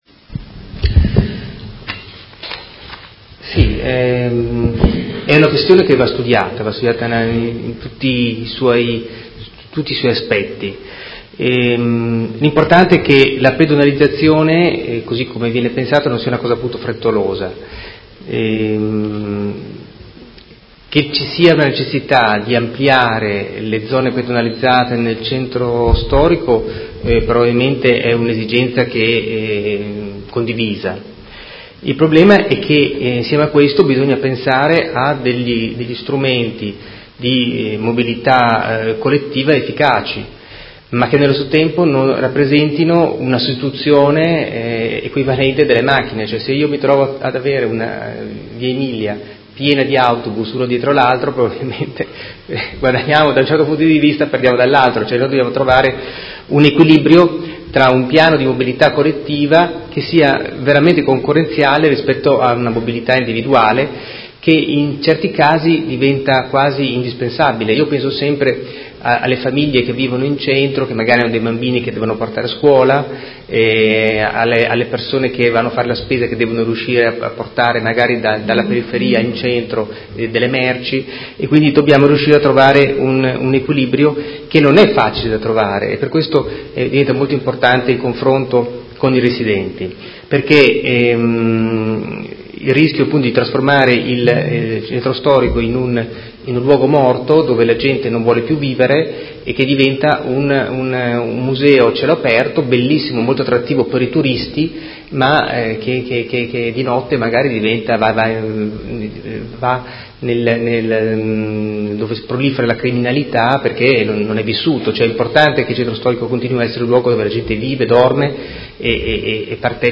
Giovanni Bertoldi — Sito Audio Consiglio Comunale